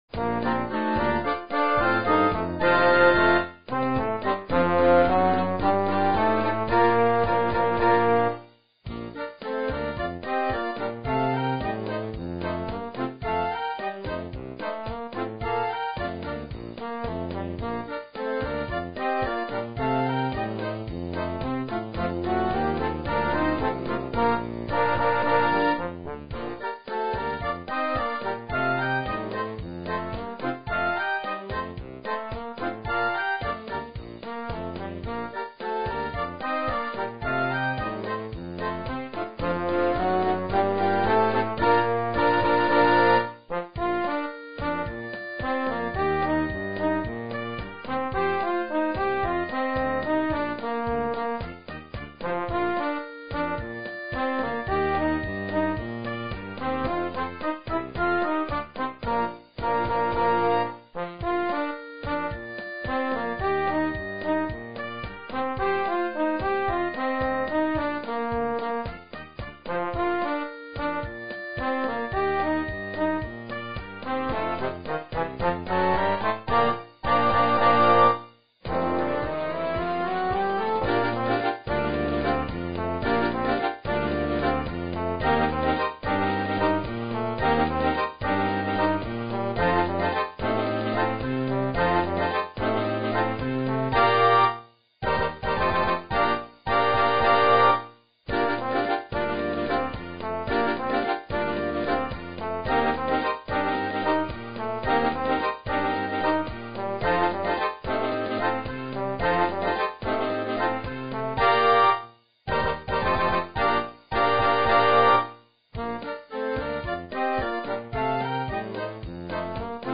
Cha cha cha marciabile